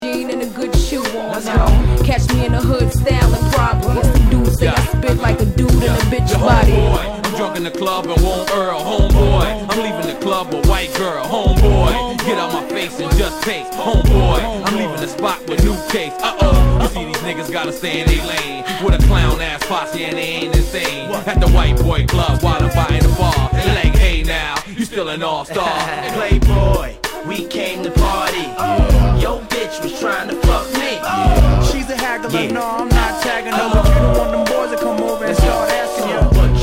Category       レコード / vinyl 12inch
Tag       EASTCOAST 　 HIP HOP